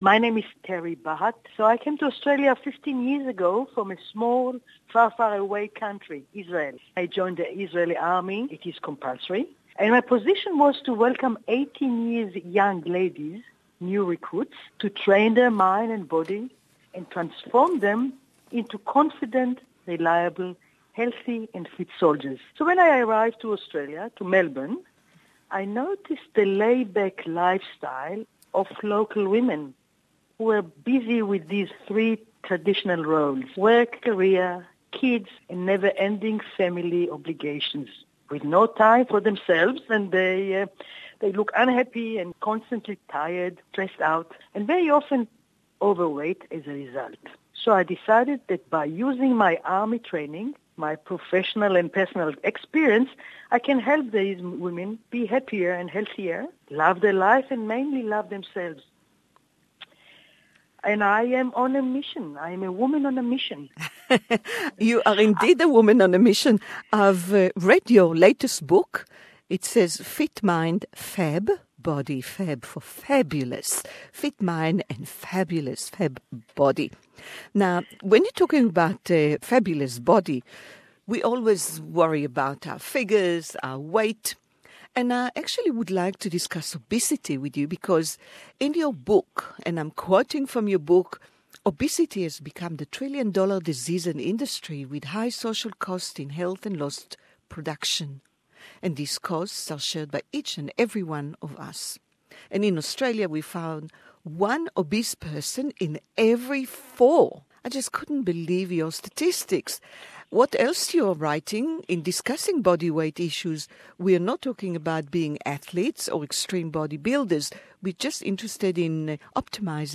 English Interview